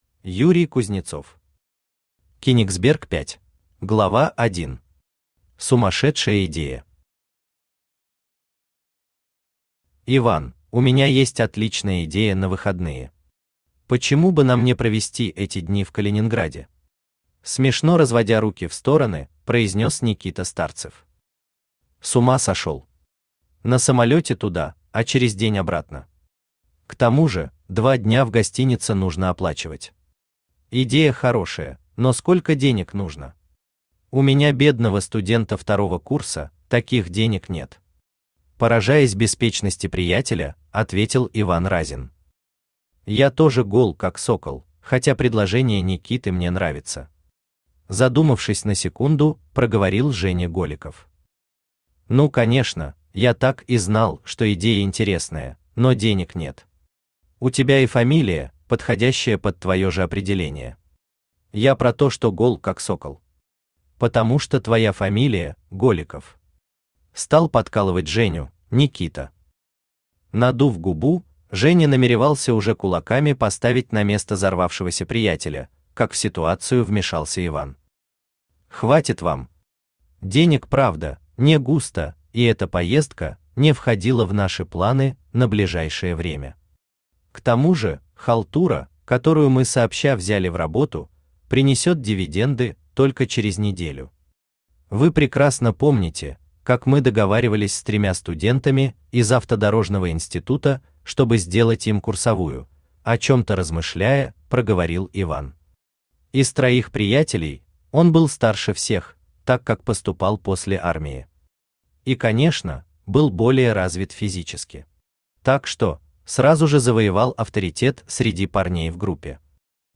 Аудиокнига Кенигсберг 5 | Библиотека аудиокниг
Aудиокнига Кенигсберг 5 Автор Юрий Юрьевич Кузнецов Читает аудиокнигу Авточтец ЛитРес.